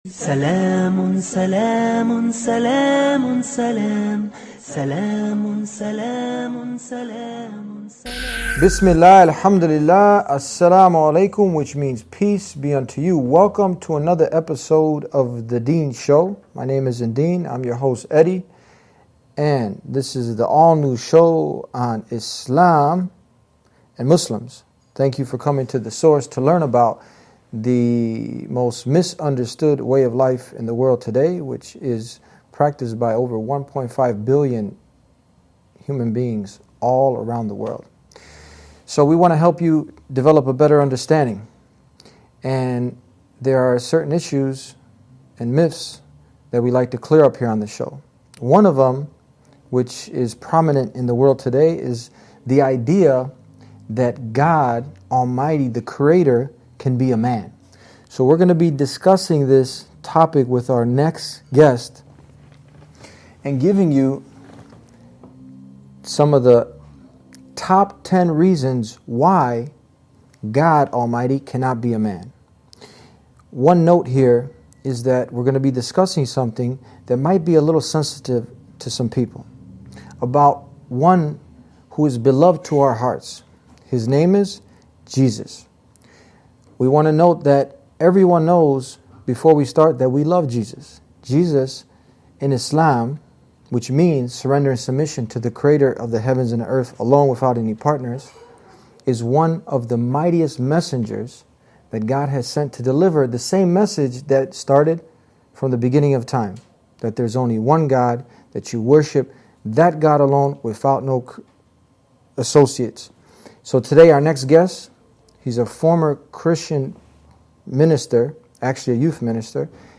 Why This Conversation Matters